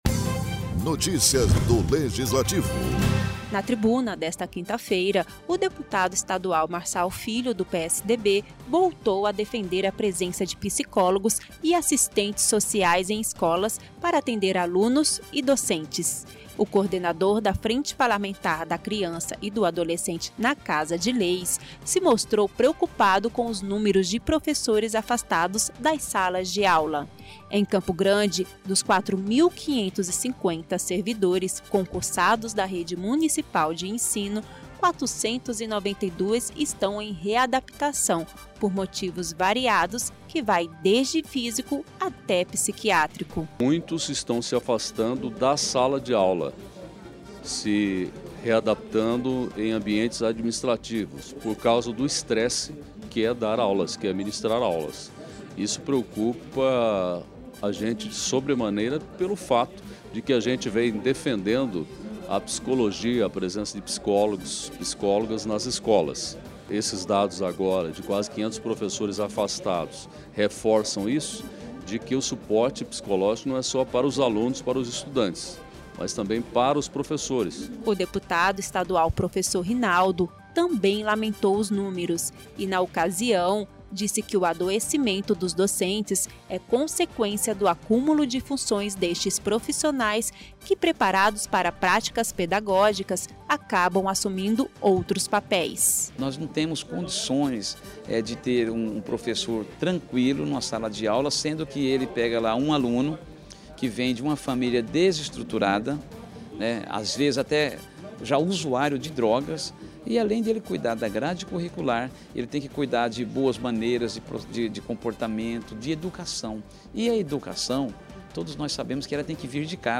Na tribuna durante a sessão ordinária desta quinta-feira, deputados estaduais preocupados com os números de afastamento de professores da sala de aula, voltaram a defender a contratação de psicólogos e assistentes sociais para atender alunos e docentes.